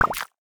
UIClick_Bubbles Splash Twisted Vegetables 02.wav